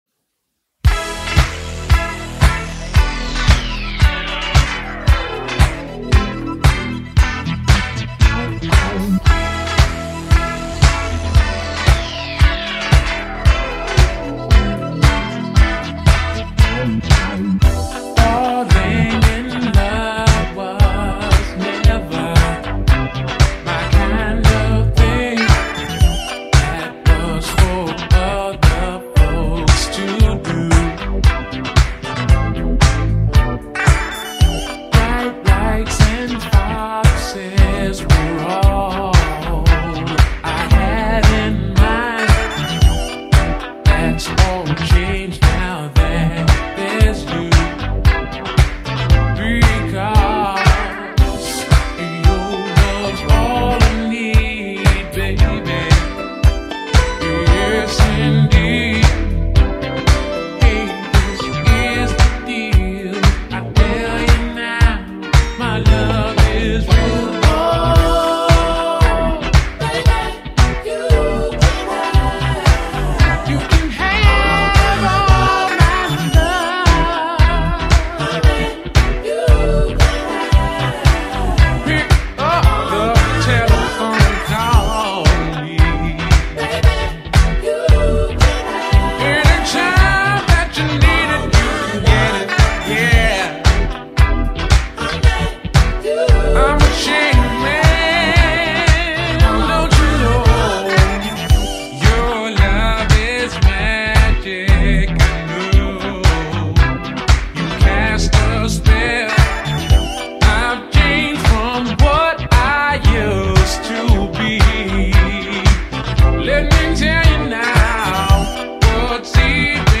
uptempo boogie cut
slamming synth work half way in